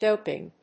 /dóʊpɪŋ(米国英語), dˈəʊpɪŋ(英国英語)/